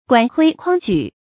管窺筐舉 注音： ㄍㄨㄢˇ ㄎㄨㄟ ㄎㄨㄤ ㄐㄨˇ 讀音讀法： 意思解釋： 比喻學識淺陋，見聞不廣。